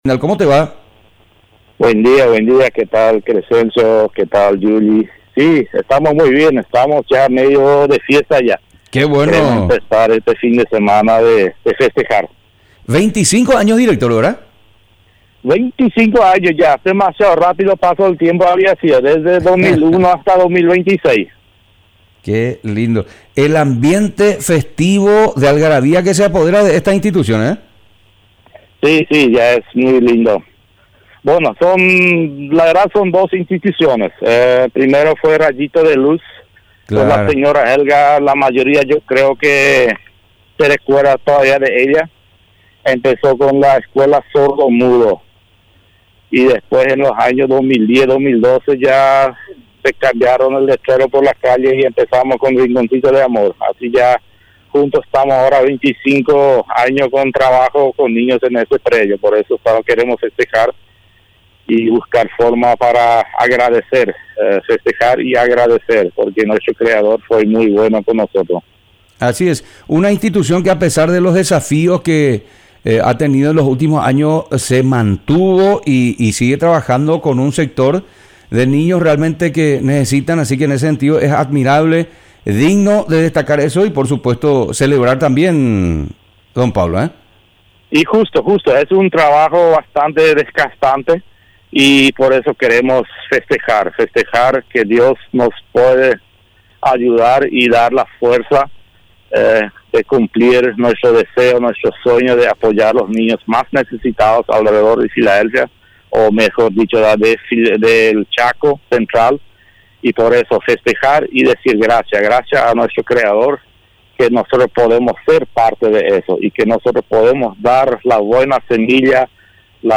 Entrevistas / Matinal 610 Fundación Rinconcito de Amor cumple 25 años 06/03/2026 Mar 06 2026 | 00:16:48 1x 00:00 / 00:16:48 Subscribe Share RSS Feed Share Link Embed